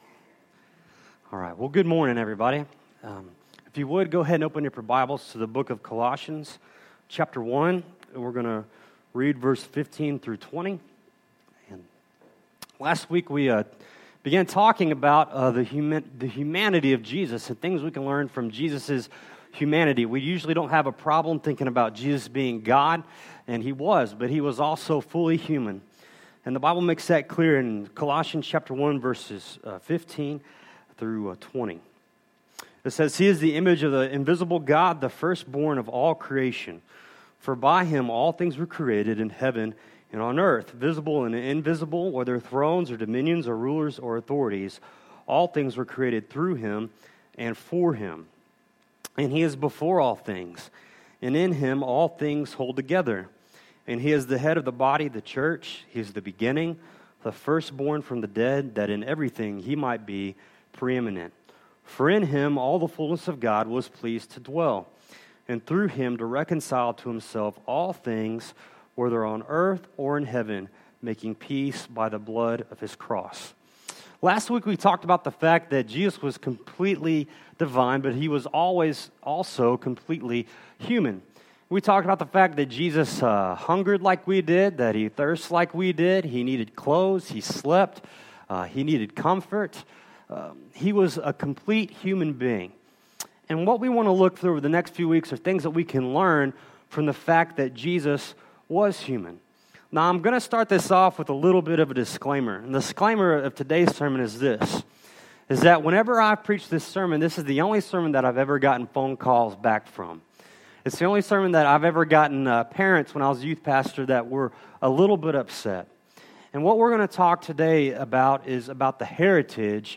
Colossians 1:15-20 Service Type: Sunday Morning Bible Text